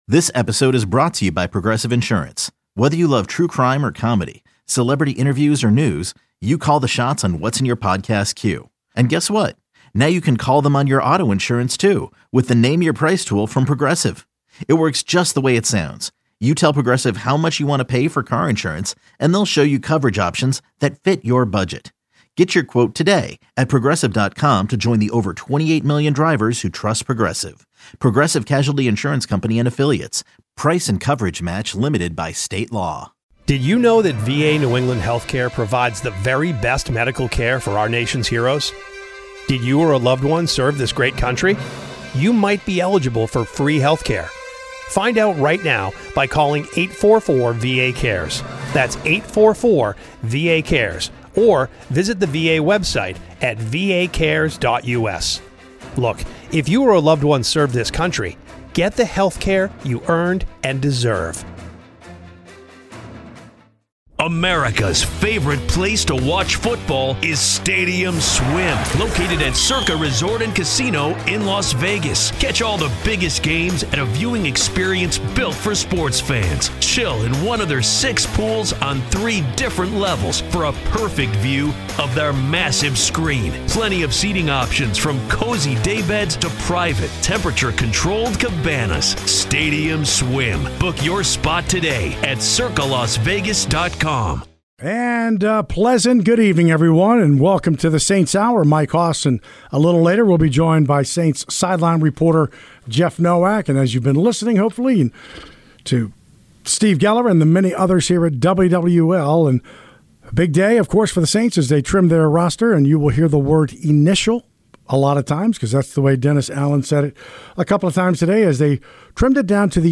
Saints Hour: The Saints' Current 53-man Roster Is A "fluid Situation" New Orleans Saints podcast To give you the best possible experience, this site uses cookies.